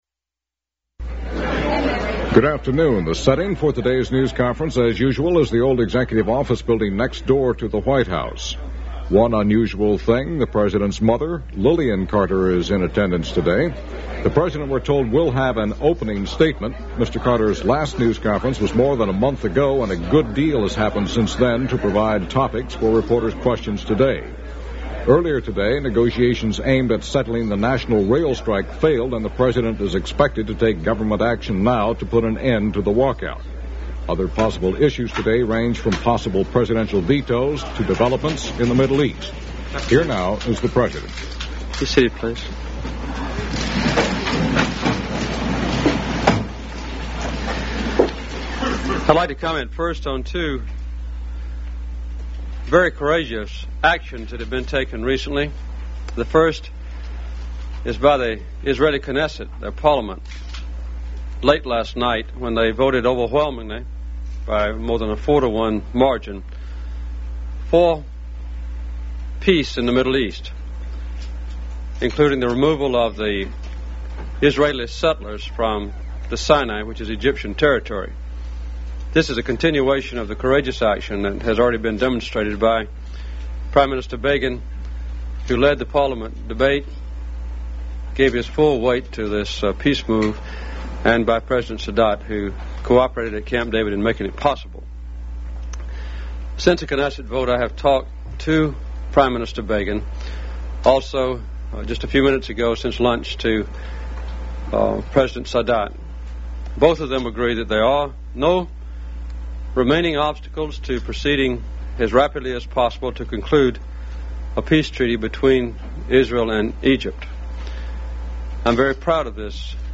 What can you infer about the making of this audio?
Broadcast on CBS TV, September 28, 1978.